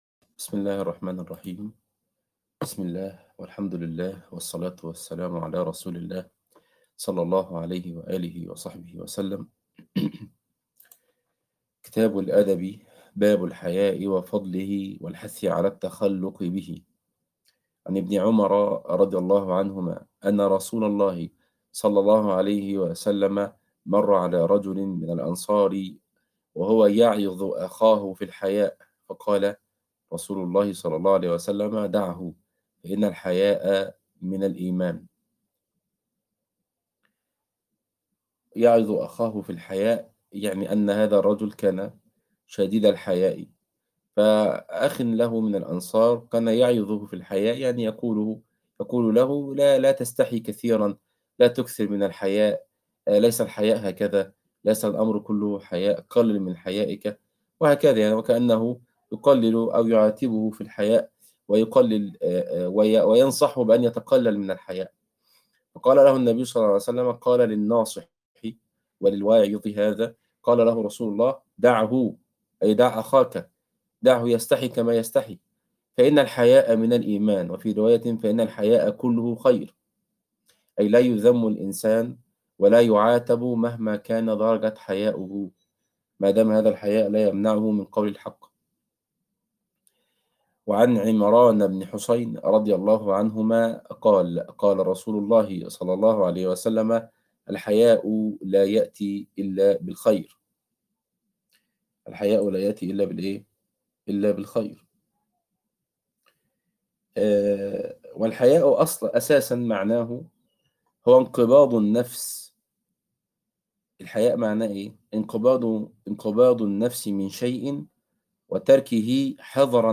عنوان المادة الدرس 21 | دورة كتاب رياض الصالحين تاريخ التحميل الجمعة 27 يونيو 2025 مـ حجم المادة 43.99 ميجا بايت عدد الزيارات 105 زيارة عدد مرات الحفظ 79 مرة إستماع المادة حفظ المادة اضف تعليقك أرسل لصديق